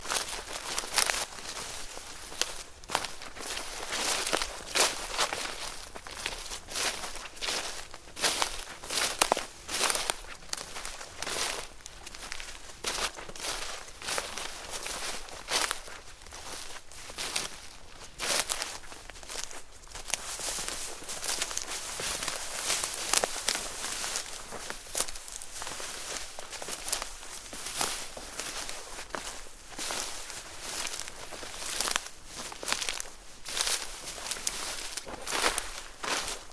Шаги среди кустов